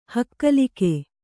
♪ hakkalike